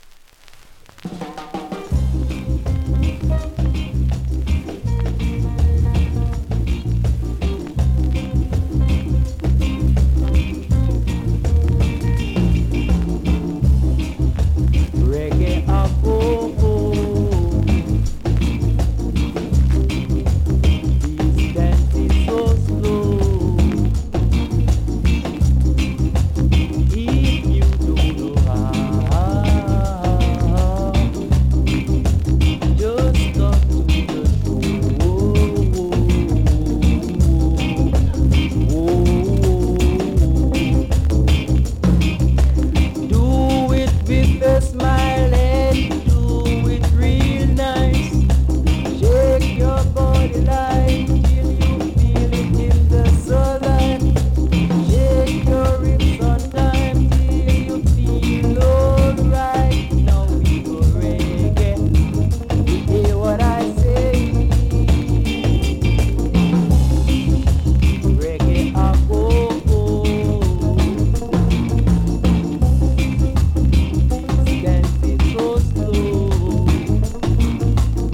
文章で伝わると良いのですが、２カ所盤の端からヒビに見える部分ありますが実際は割れてないので音にも影響ありません。
盤に少し歪みありますがプレイは問題無いレベル。